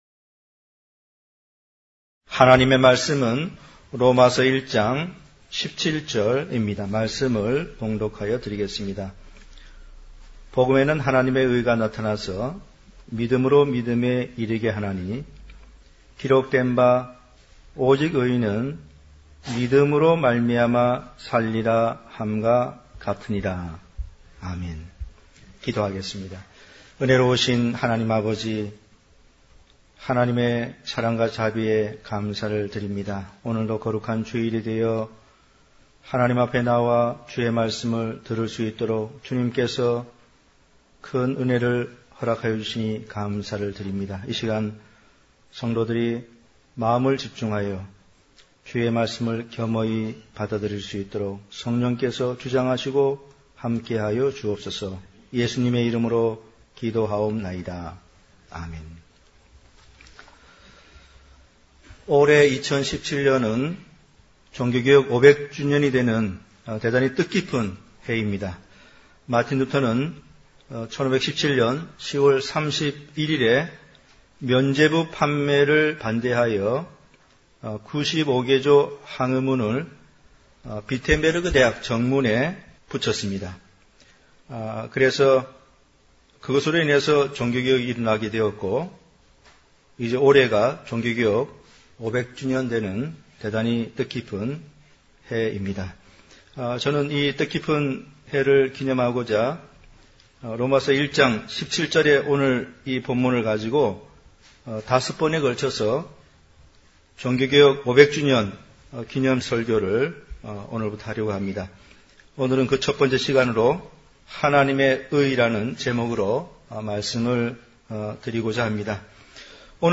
시리즈설교